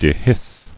[de·hisce]